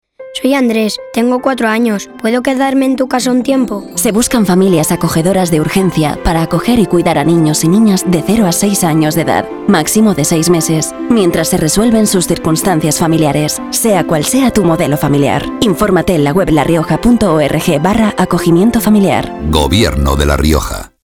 Cuña radiofónica